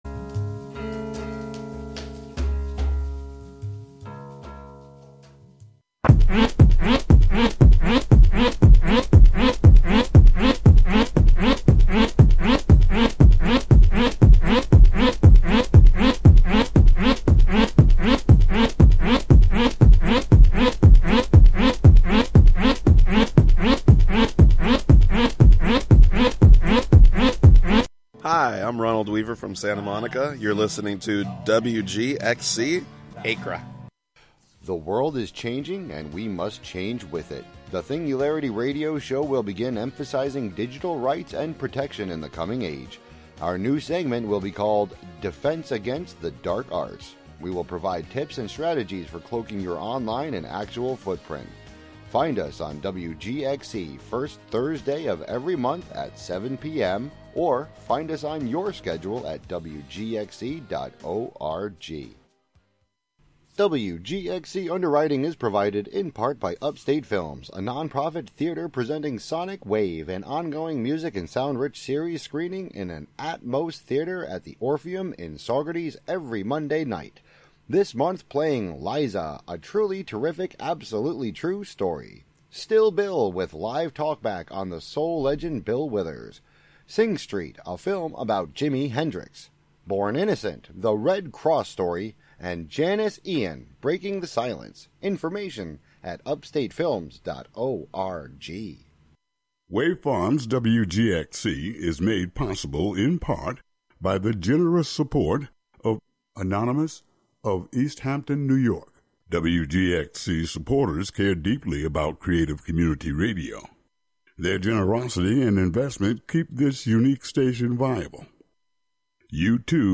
Waft through the mists of quantum time: you are in the beforetime and aftertime all at the same time. We'll encounter forgotten and found sounds: old records, field recordings, EVP + EMF, warped vocals... and one haunted wurlitzer organ.